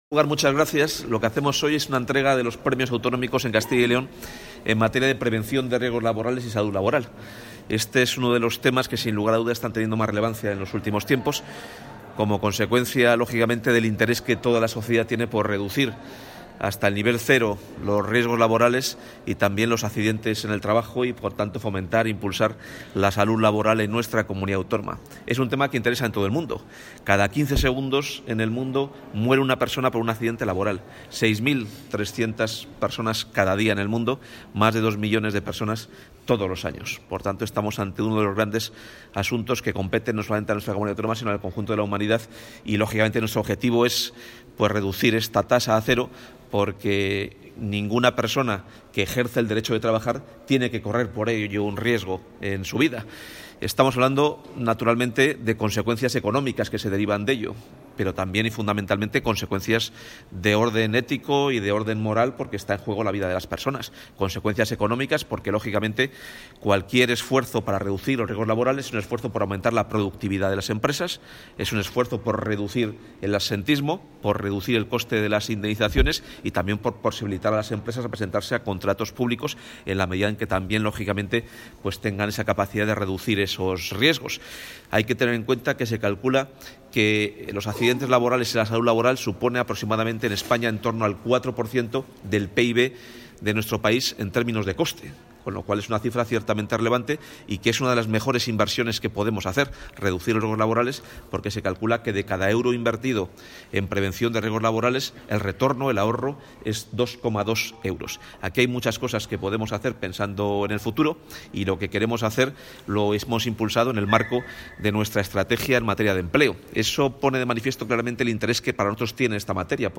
El consejero de Empleo ha entregado los Premios a la Prevención de Riesgos Laborales de Castilla y León en el salón de actos del...
Declaraciones del consejero de Empleo.